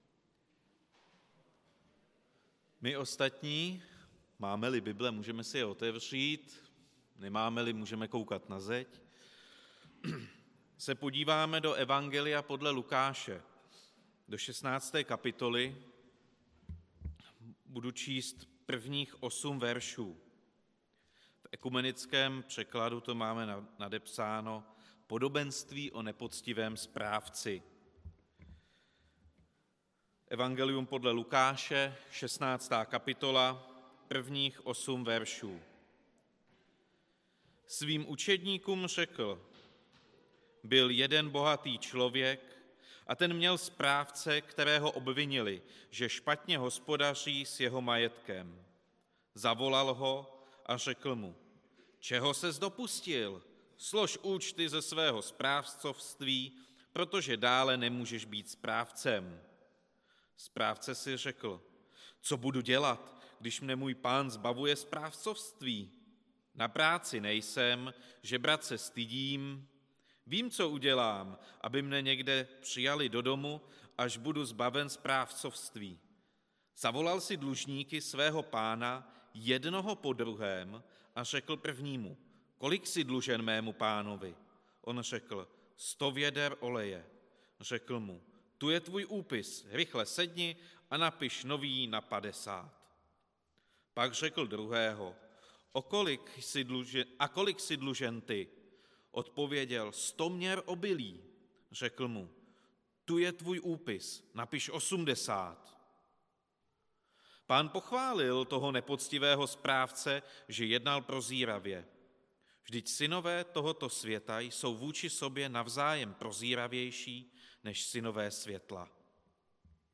Kázání
Událost: Kázání
Místo: Římská 43, Praha 2